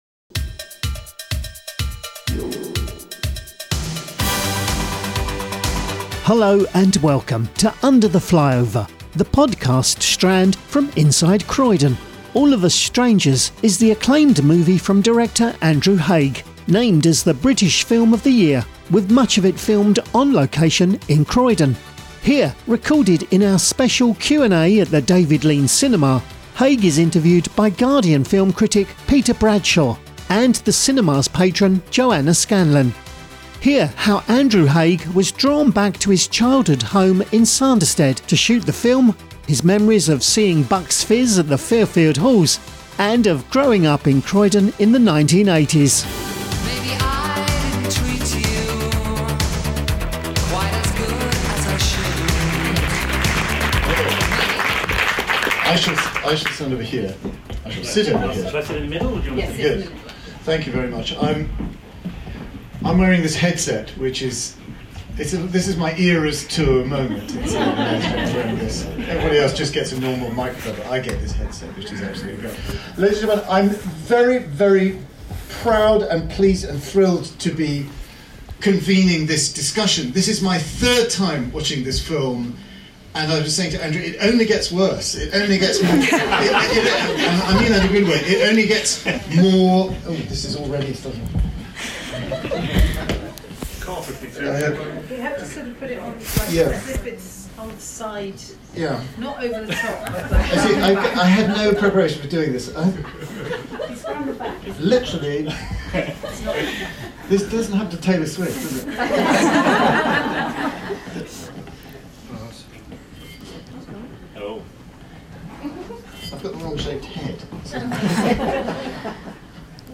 In April, filmmaker Andrew Haigh visited the David Lean Cinema for a screening of his latest film, All Of Us Strangers.
Under-The-Flyover-with-Andrew-Haigh-at-The-David-Lean-Cinema.mp3